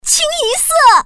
Index of /hall_shop/update/2891/res/sfx/common_woman/